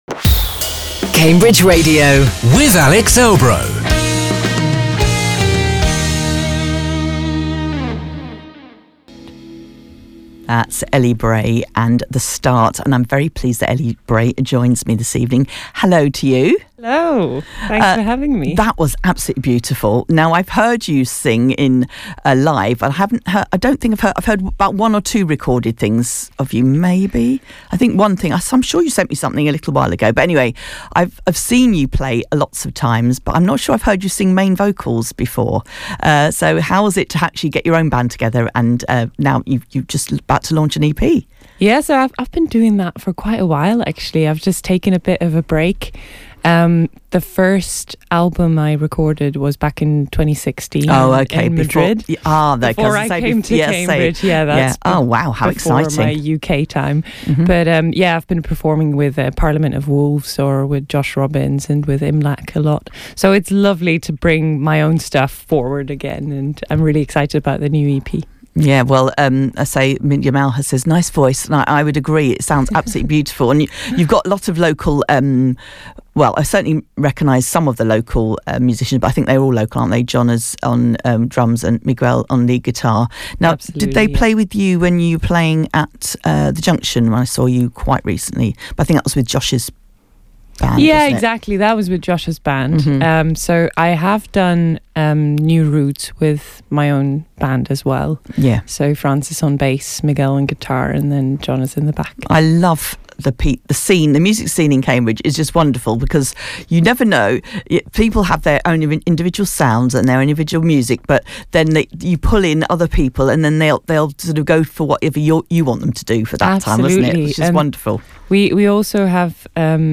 Cambridge singer-songwriter